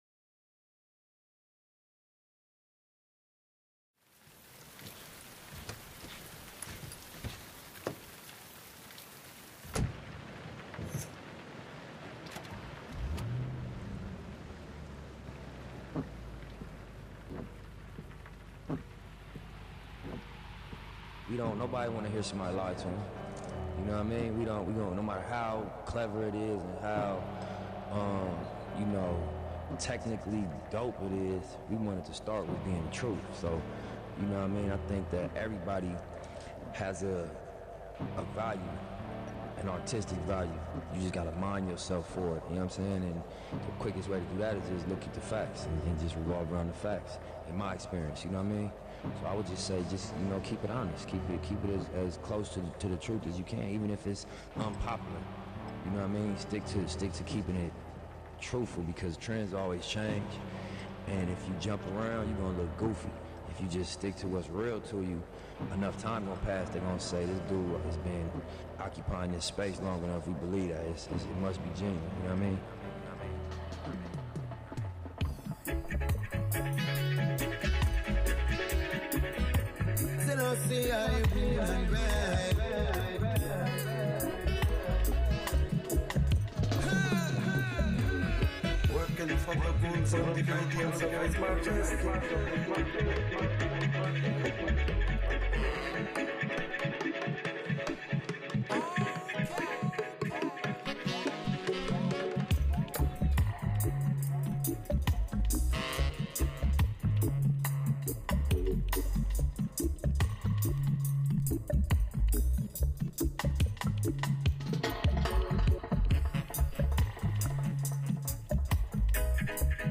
Latest dub mix